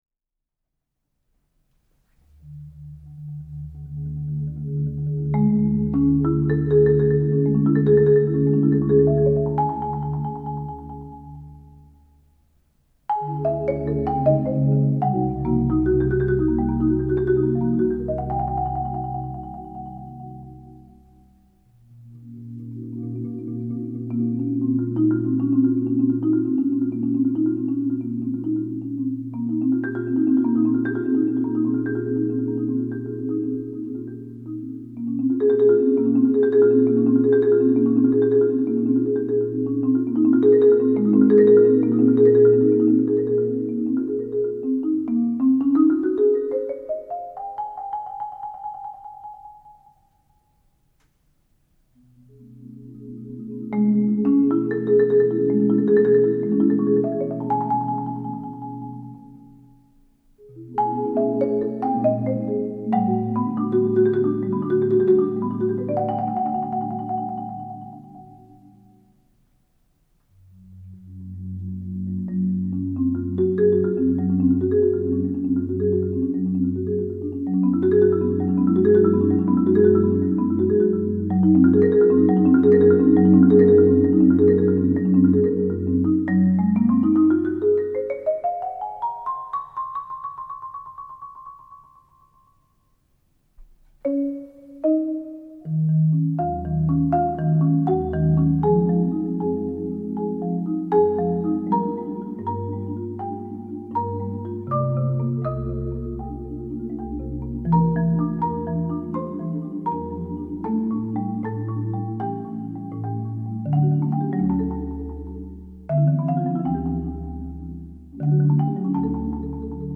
chamber music classical music string orchestra